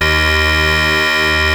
OSCAR 15 D#2.wav